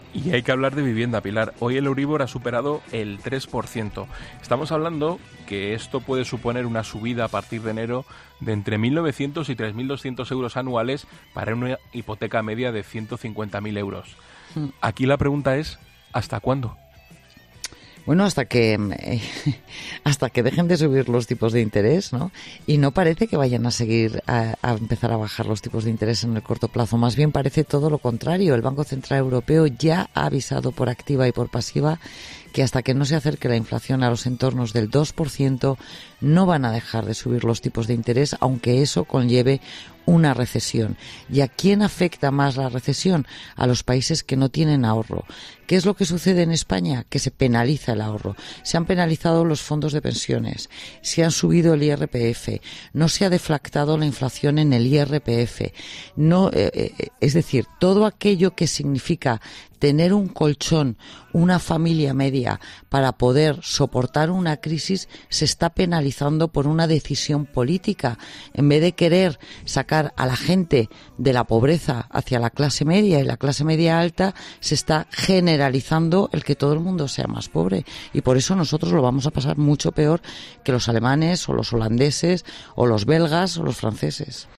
Pilar García De La Granja explica en La Linterna los motivos por lo que España será de los que más sufran la subida de tipos de interés